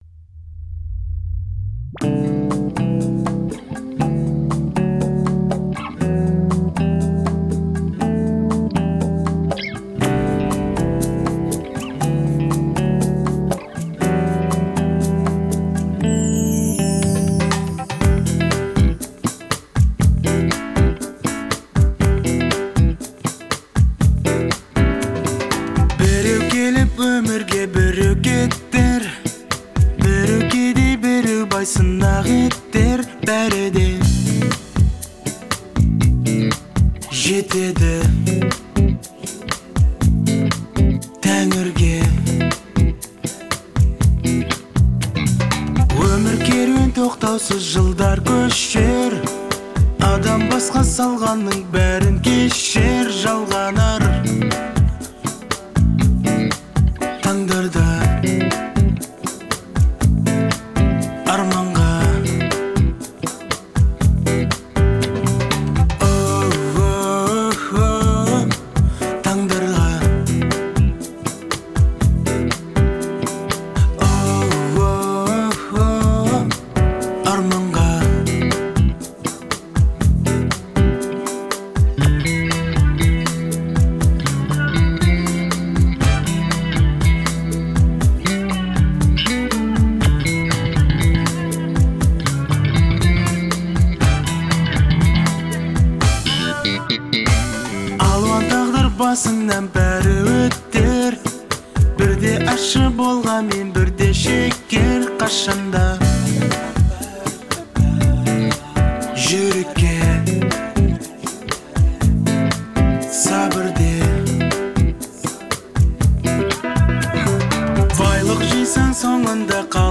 выполненная в жанре поп с элементами фолка.